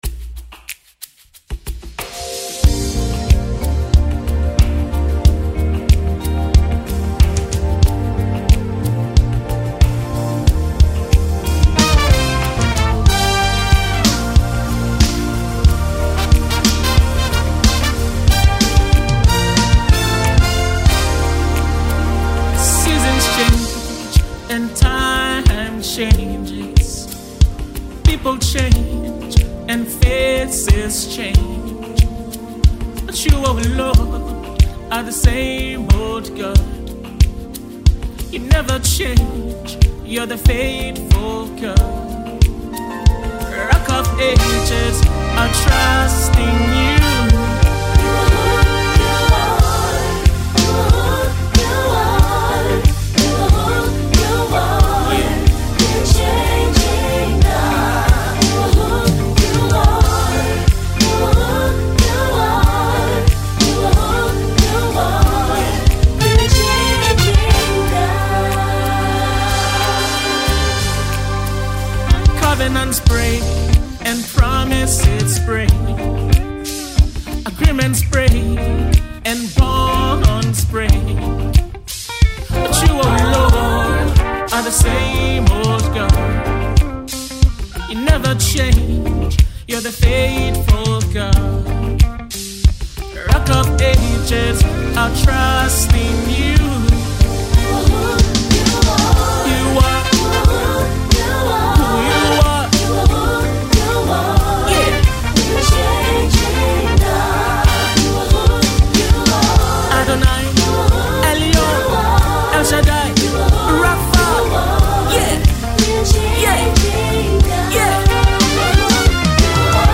contemporary worship single